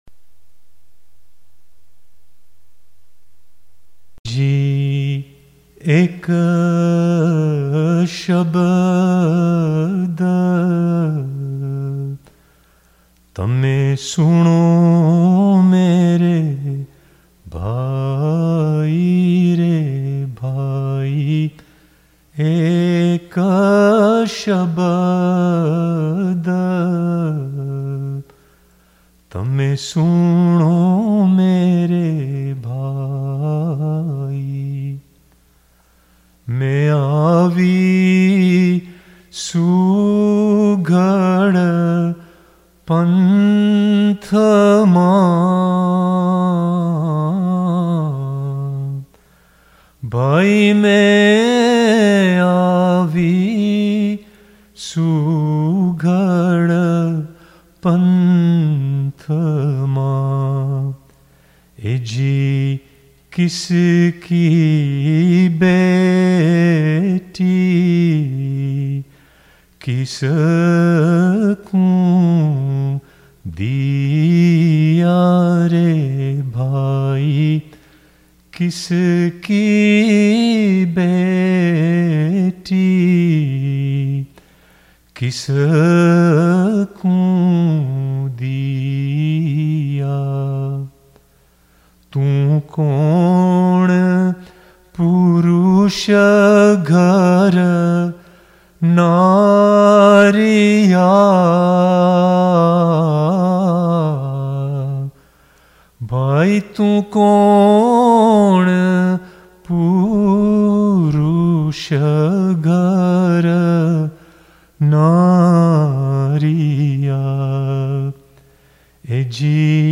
in a reflective tempo